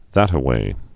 (thătə-wā)